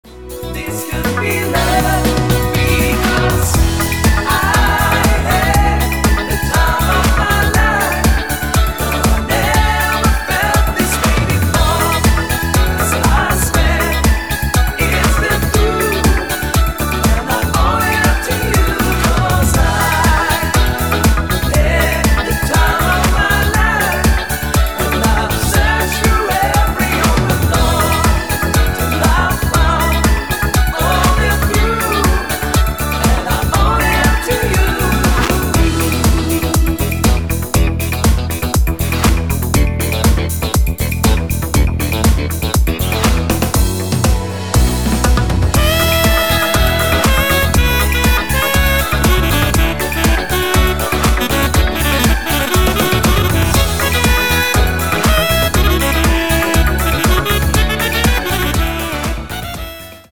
Dirty Discofox dancing